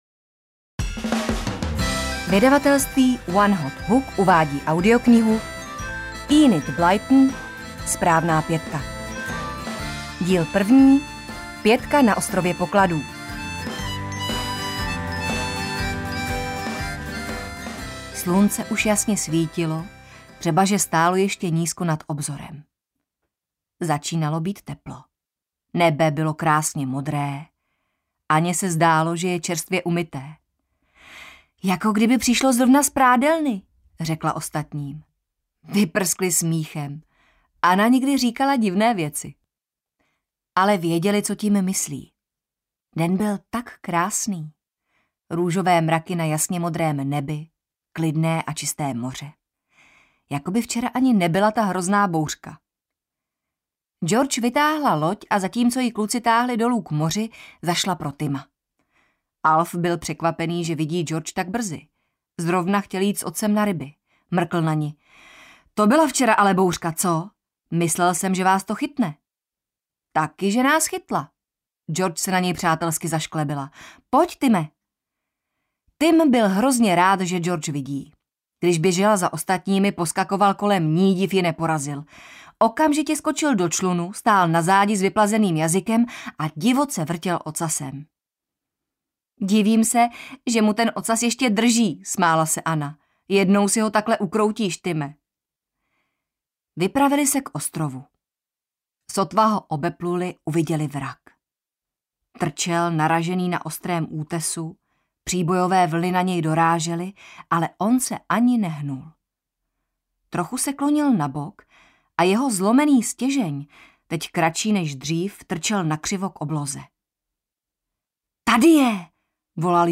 SPRÁVNÁ PĚTKA na ostrově pokladů audiokniha
Ukázka z knihy
spravna-petka-na-ostrove-pokladu-audiokniha